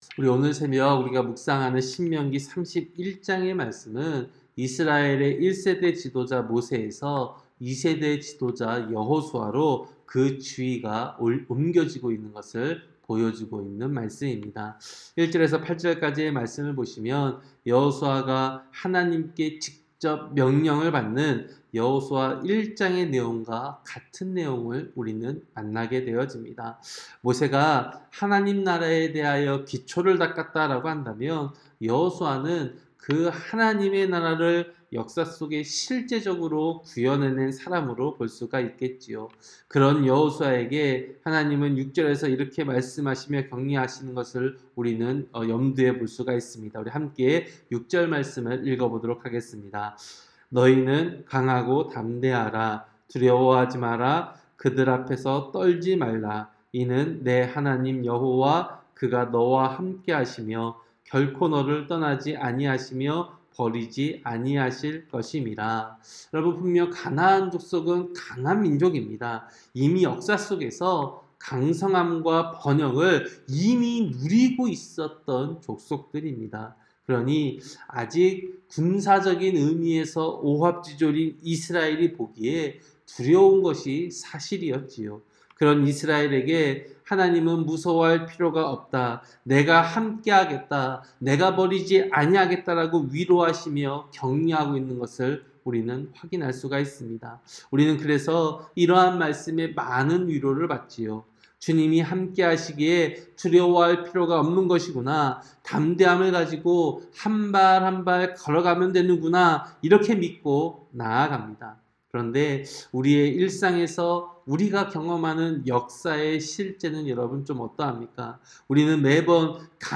새벽설교-신명기 31장